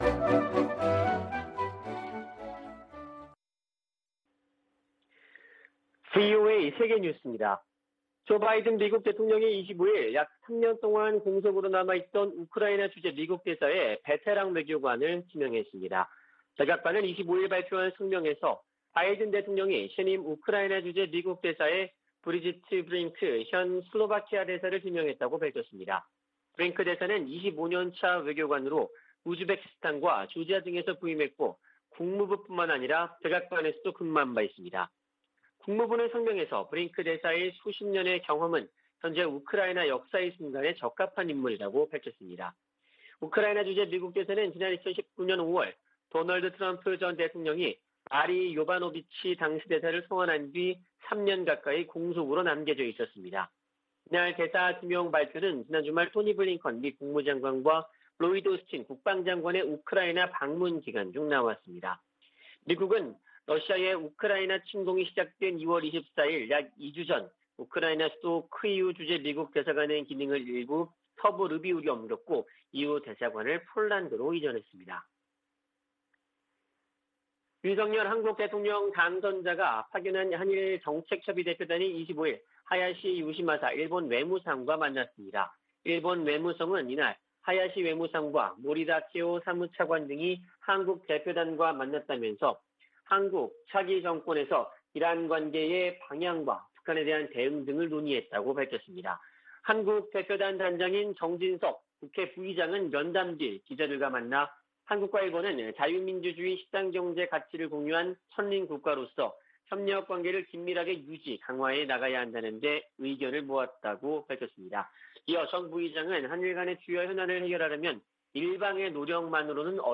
VOA 한국어 아침 뉴스 프로그램 '워싱턴 뉴스 광장' 2022년 4월 26일 방송입니다.